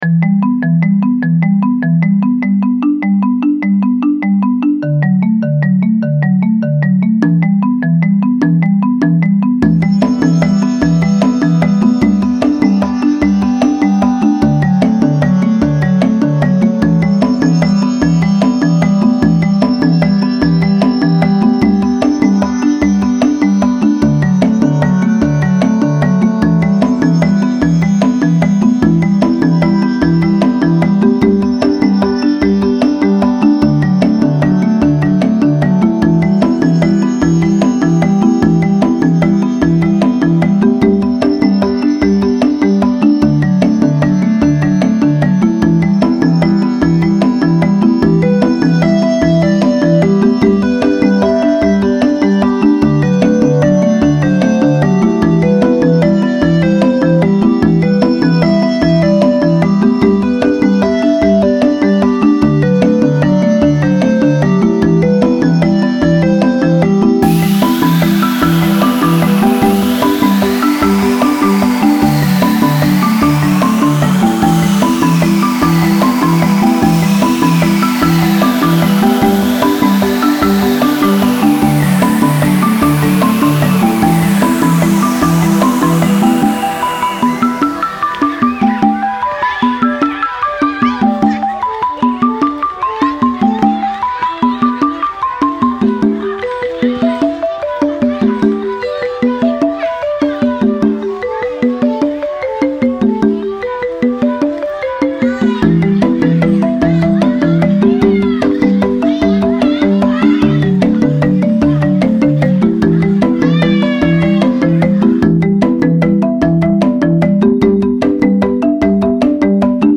This song started with a minor chord progression I came up with on my marimba. I then began adding hand drums (Afro-Blue 2-drum rhythm).
In my song I try to capture some of the feelings that I imagine followed him throughout his day: anxiety, loneliness, grief, loss and wonder.
The last layer of sound I added was of kids playing, recorded at a playground down the street from my house.